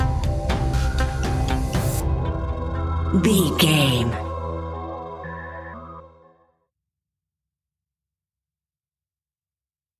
Aeolian/Minor
scary
ominous
eerie
synthesizer
drum machine
ticking
electronic music